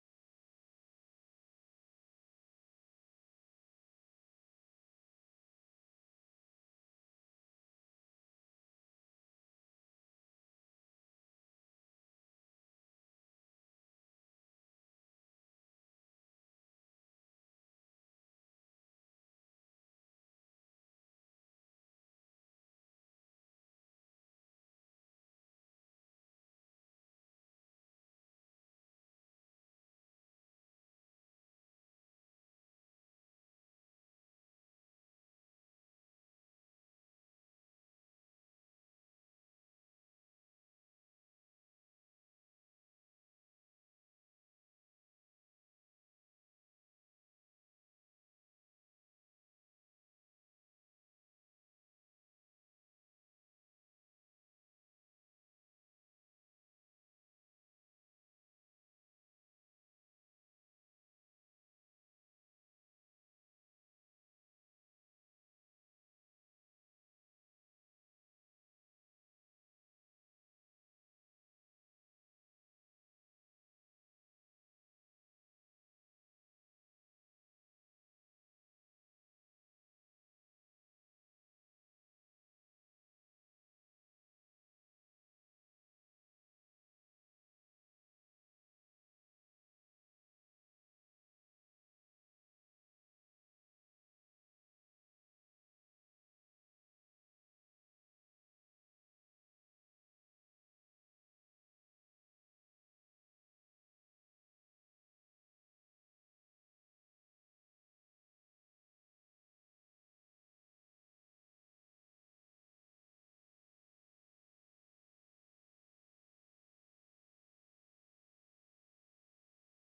Praise and Worship at FWC on December 15 2024